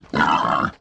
Index of /App/sound/monster/wild_boar_god
dead_1.wav